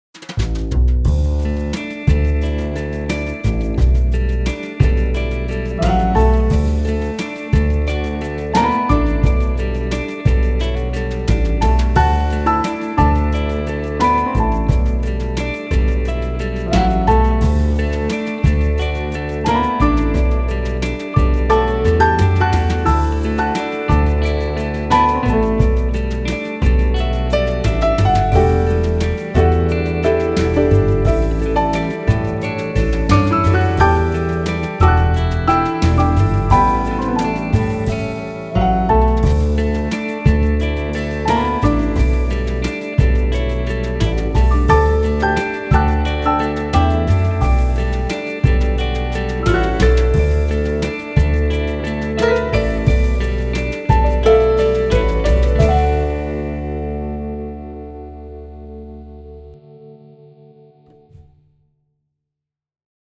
一种富有表现力的古典三角钢琴，以严谨细致的采样获得真实的音色和独特的风格。
经典复古三角钢琴
独一无二的非传统声音
采样1905年的复古三角钢琴
声音类别: 钢琴